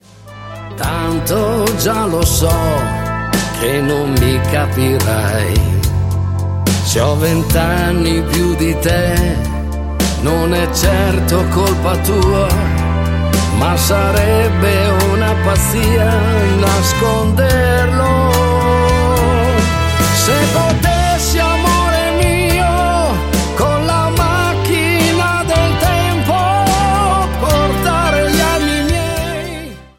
TERZINATO  (04,09)